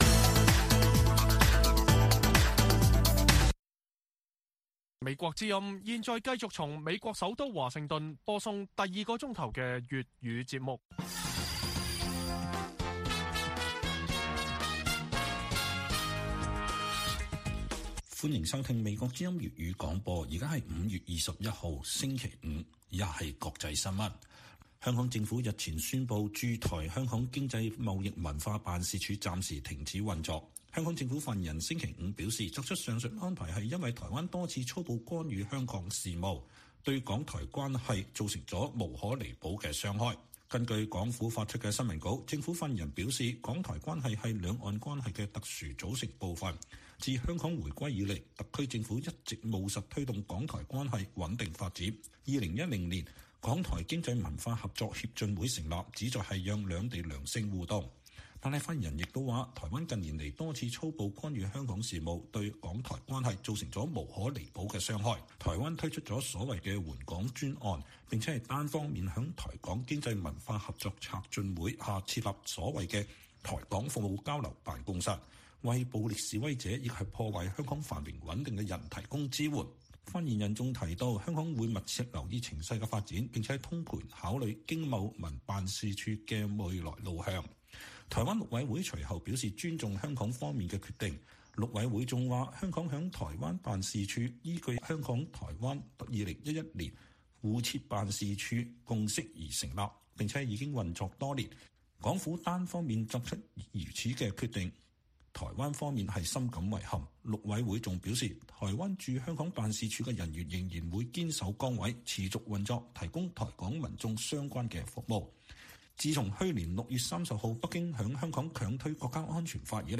粵語新聞 晚上10-11點: 香港回應駐台辦事處停運指責台“粗暴干預”香港事務